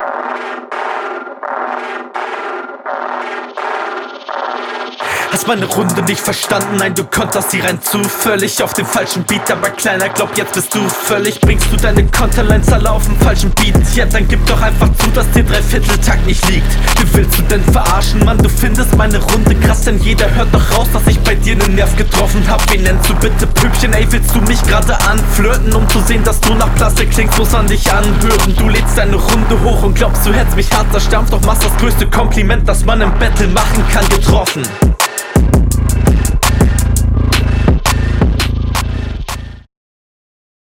Gute Pausensetzung und Flowvariationen auch in dieser Runde.